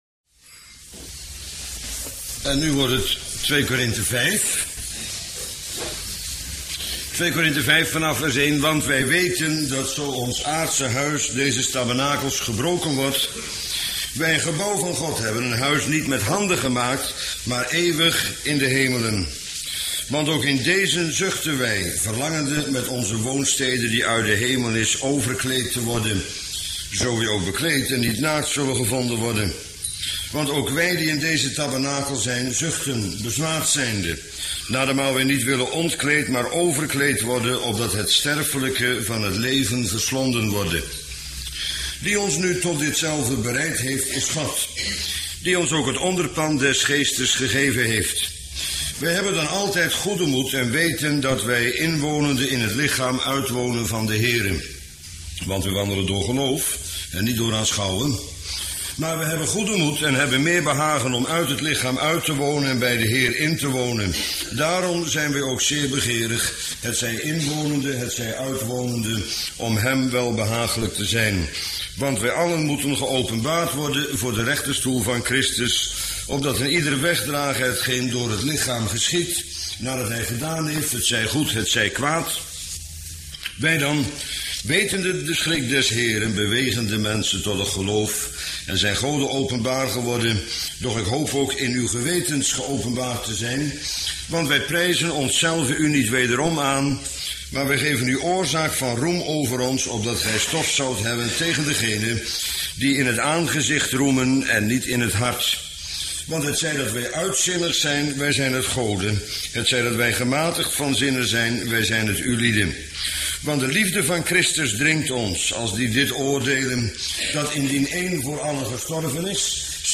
Bijbelstudie lezingen mp3.